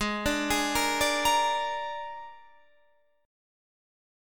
G# Suspended 2nd Flat 5th